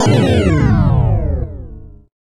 1 channel
SHIPEXPLODE.mp3